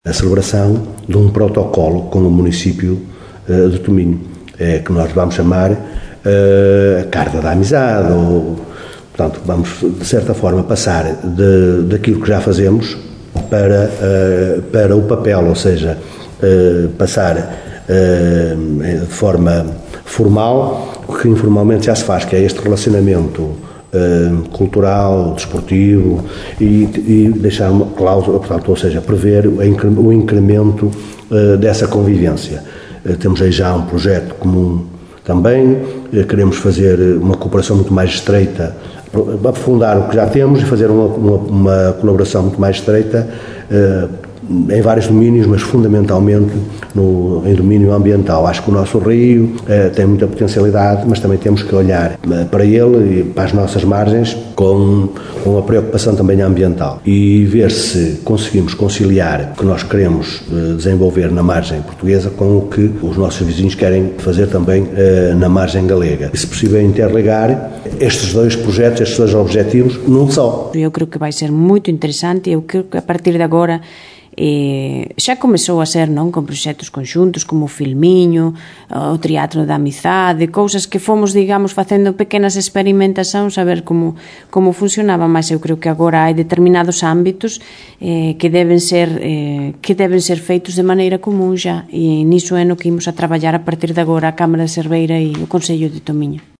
É o que revelam os autarcas das duas margens: Fernando Nogueira, de Vila Nova de Cerveira, e Sandra Alvarez, de Tominho: